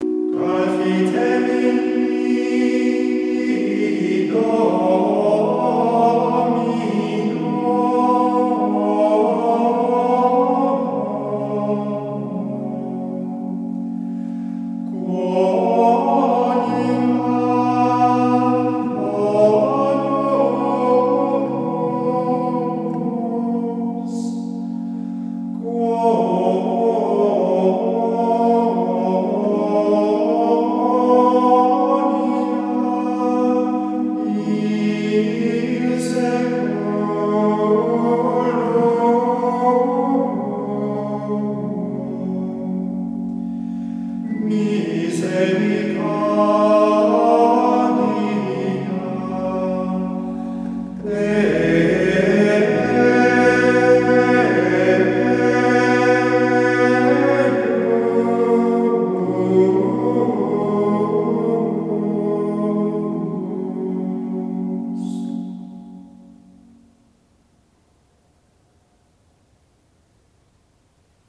Professional recordings: